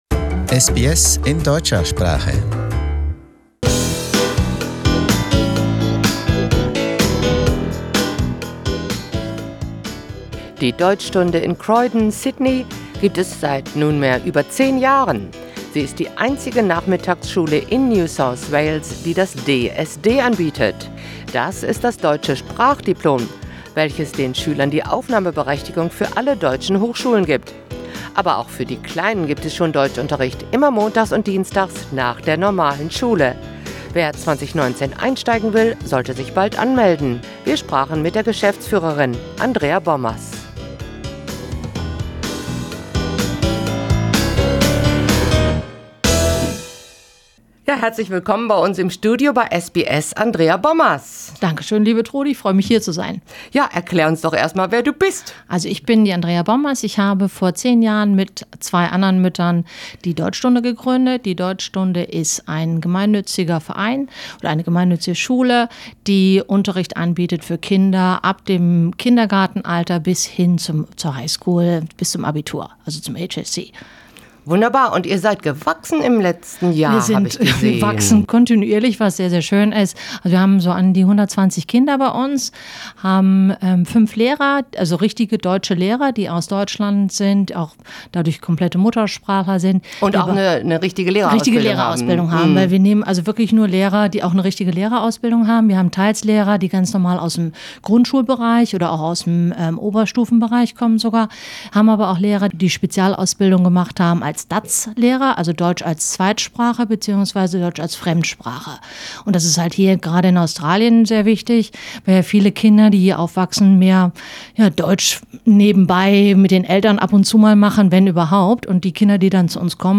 Im Gespräch: Deutschstunde nimmt neue Schüler auf